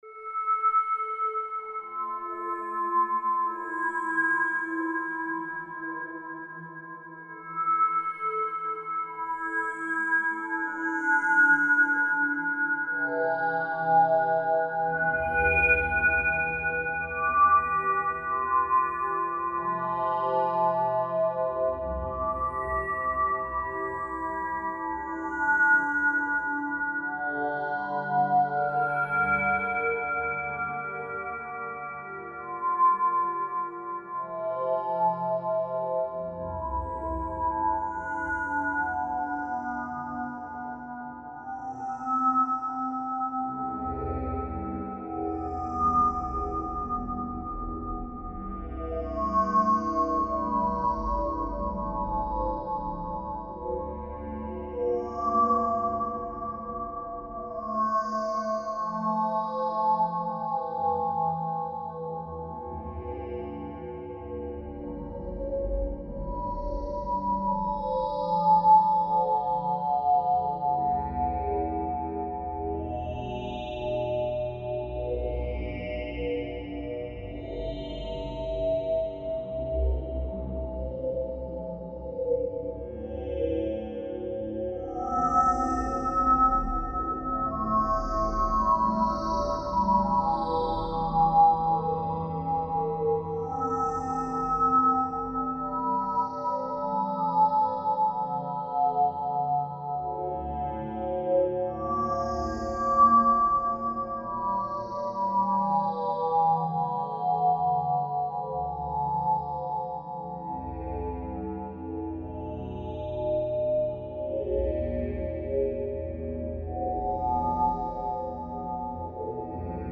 DARK AMBIENT SPACE